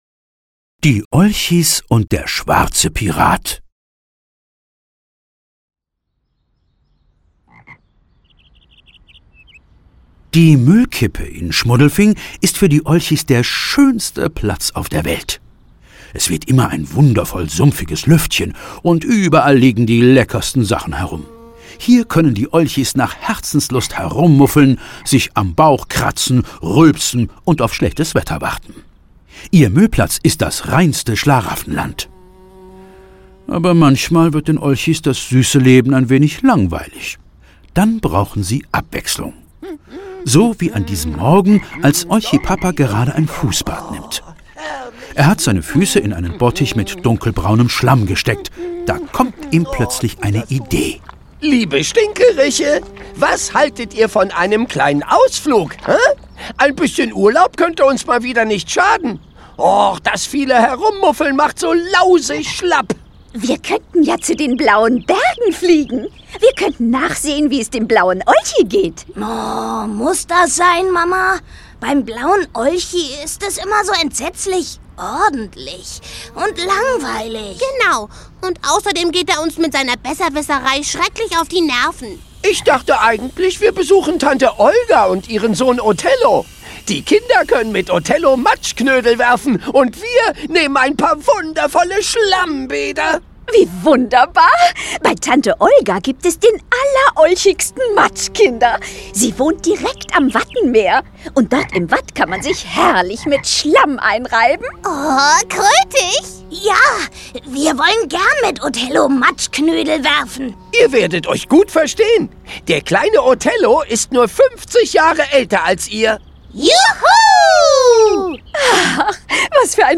Die Olchis und der schwarze Pirat - Erhard Dietl - Hörbuch